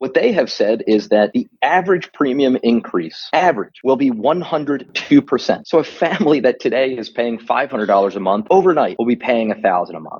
Congressman Brendan Boyle of Pennsylvania said the impact would be immediate for families relying on the ACA…